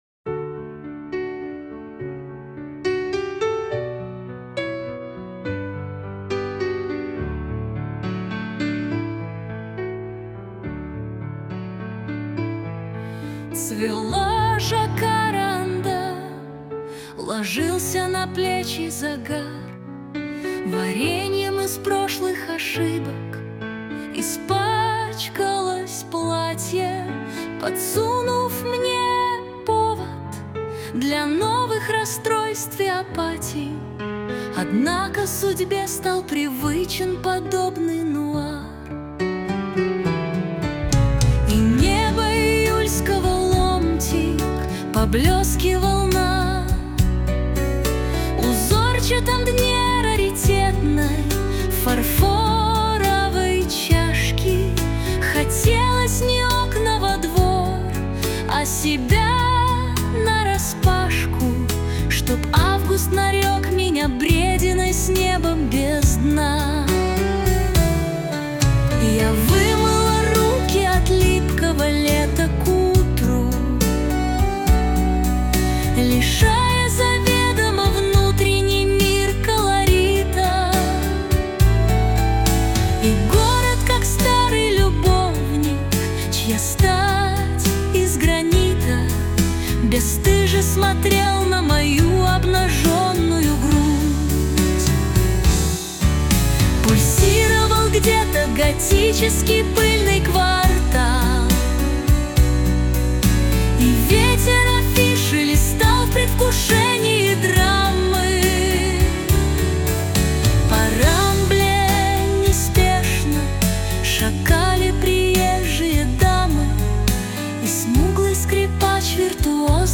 Музика не очень,смазывает находки.⁶